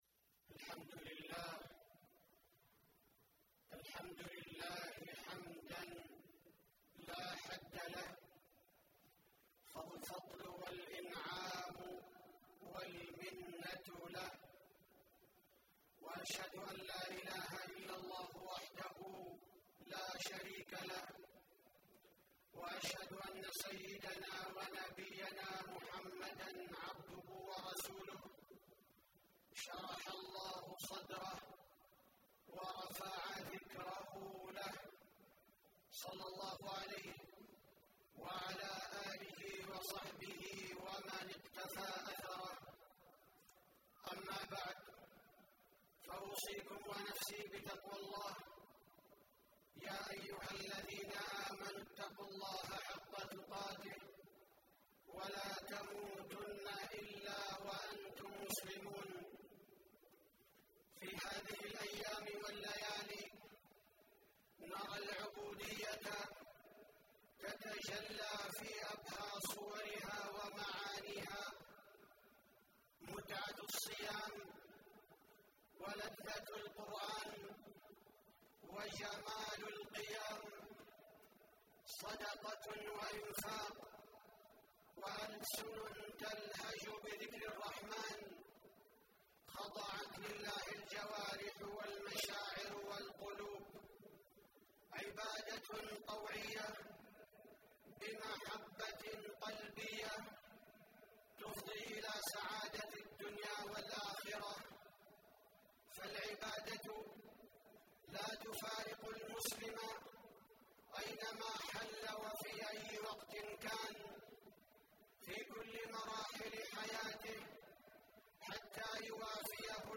تاريخ النشر ١٤ رمضان ١٤٣٨ هـ المكان: المسجد النبوي الشيخ: فضيلة الشيخ عبدالباري الثبيتي فضيلة الشيخ عبدالباري الثبيتي صفات عباد الرحمن